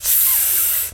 snake_hiss_07.wav